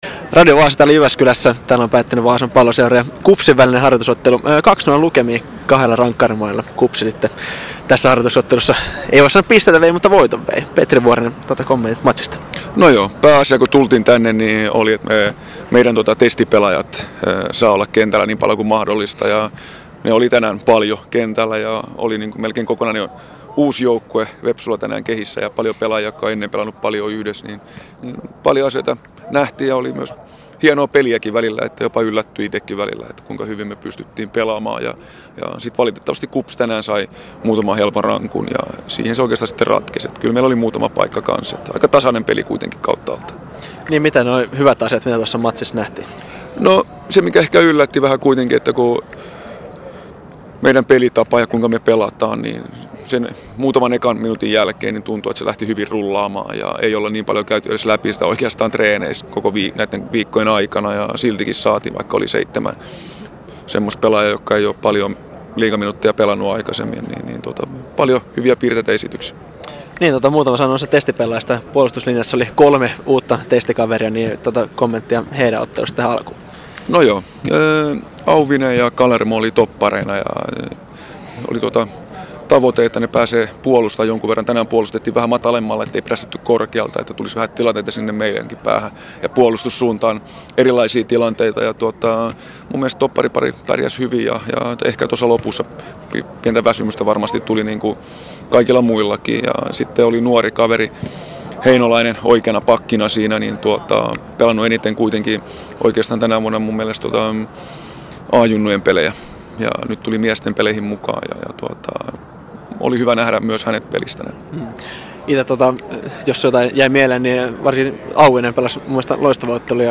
Raportti: Vepsulle tappio harjoitusottelussa